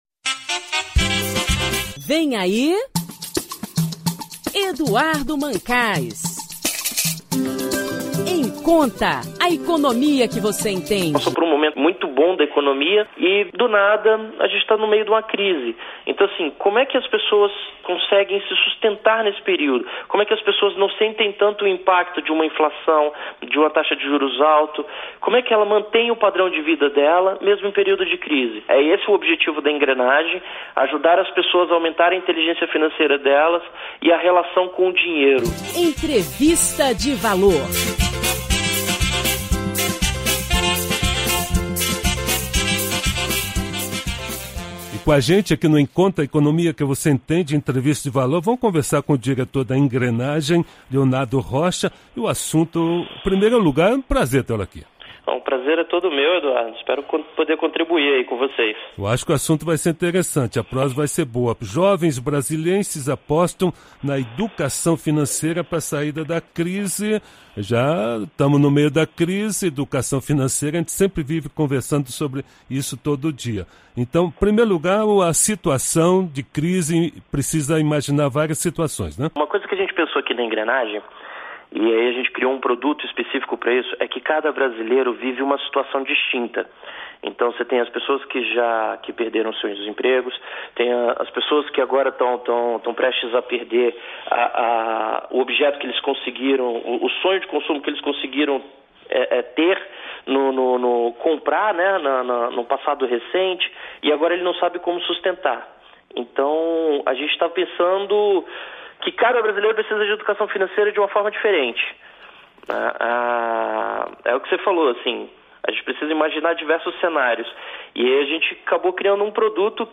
Na mídia: Entrevista para Rádio Nacional